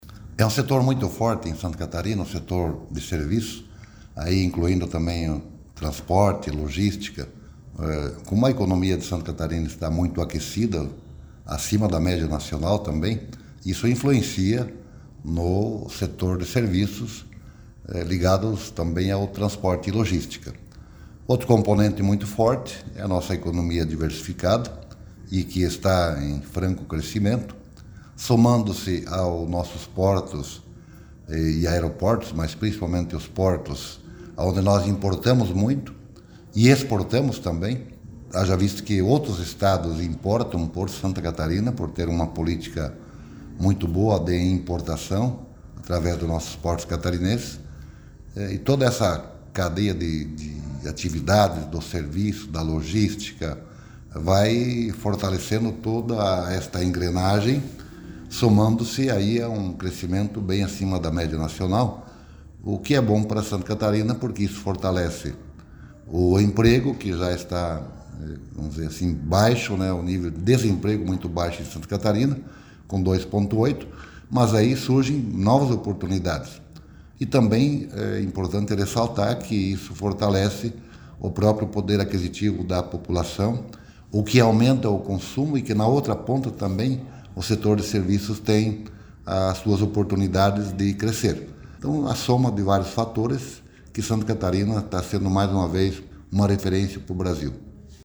Para o secretário Silvio Dreveck afirma que o aumento acima da média nacional também está relacionado à questão do emprego: